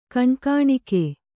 ♪ kaṇkāṇike